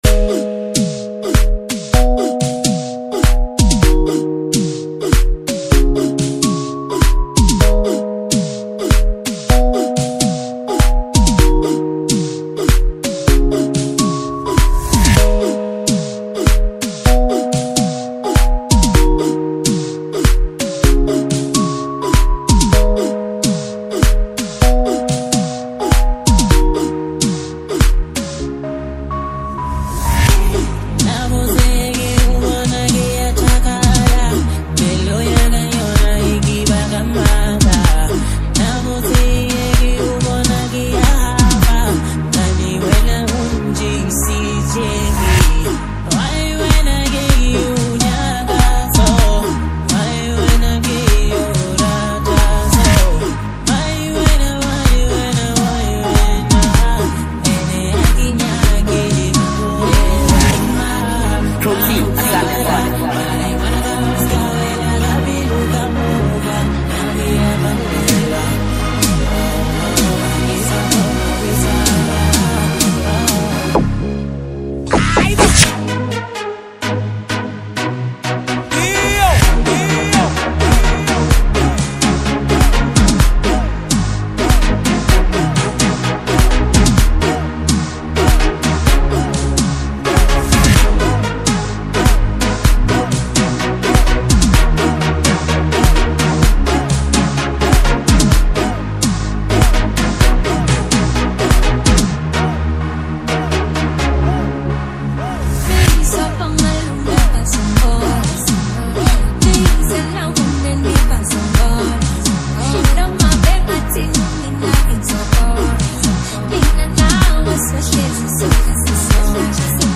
Home » Gqom